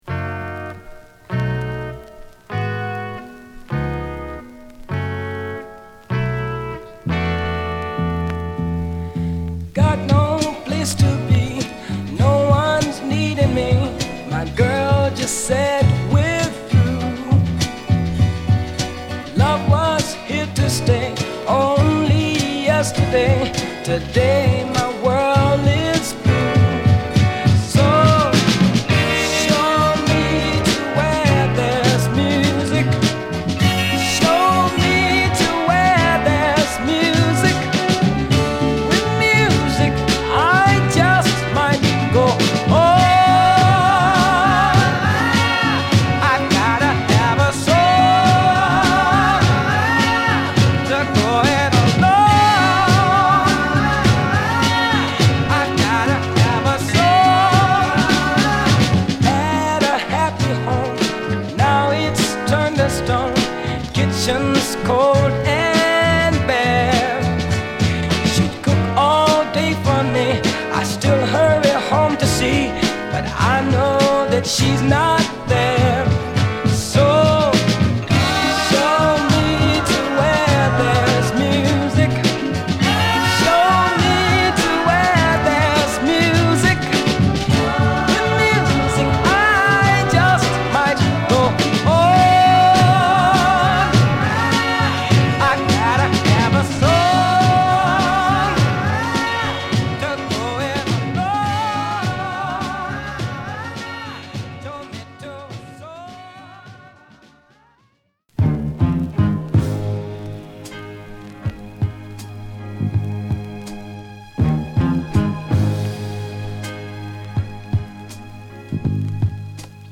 ゴスペル風のコーラスを配した、晴れやかなミディアムソウル